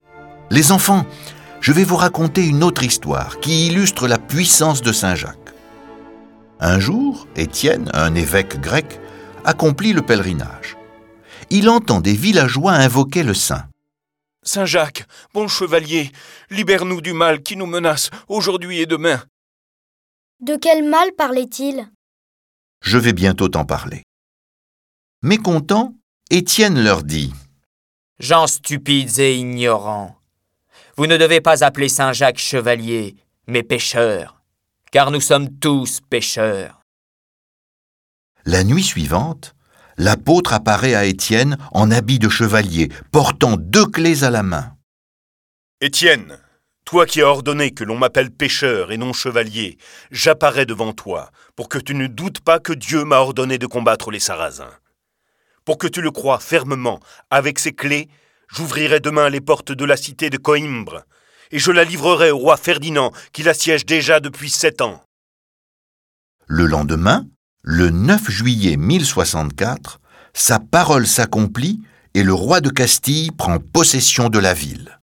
Diffusion distribution ebook et livre audio - Catalogue livres numériques
Trois enfants interrogent leur grand-père pour découvrir la vie, les miracles, la légende de ce cavalier qui défendit l’Occident contre les Sarrasins. Cette version sonore de la vie de Jacques est animée par huit voix et accompagnée de plus de trente morceaux de musique classique.